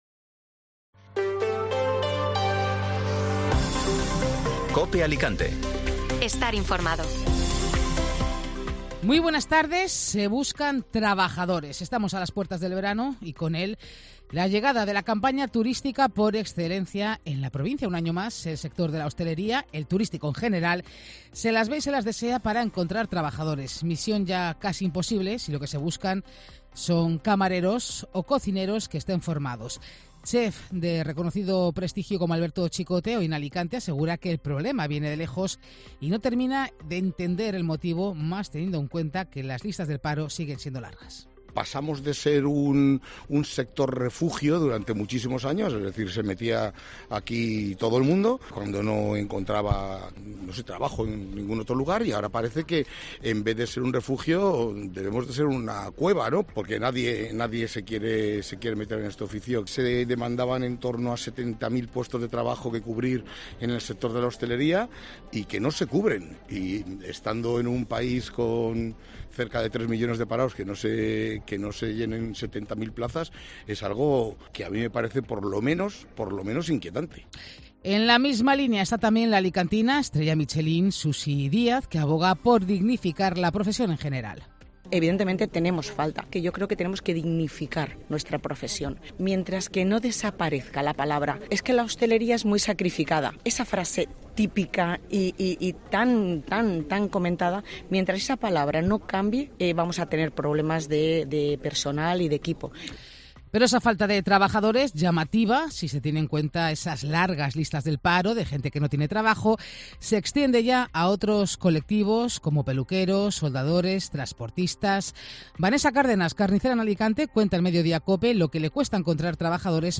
Informativo Mediodía Cope Alicante ( Mates 30 de mayo)